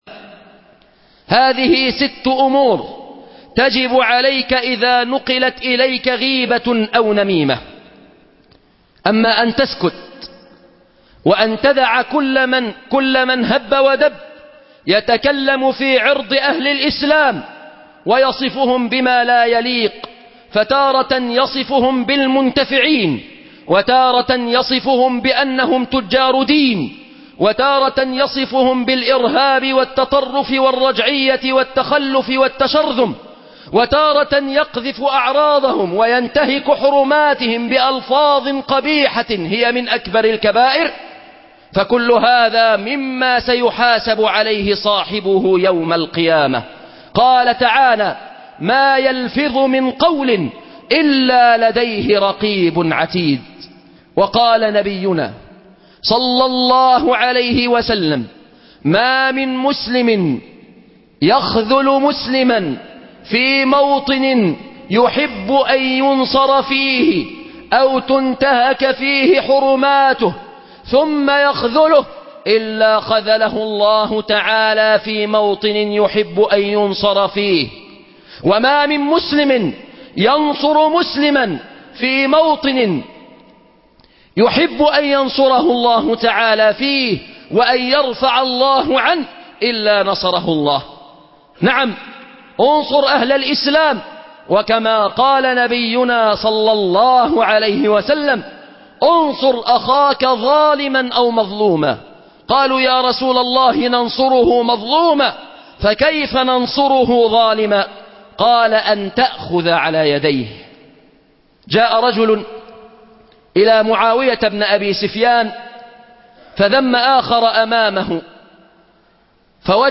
عنوان المادة امسك لسانك (درس بمسجد الزهراء)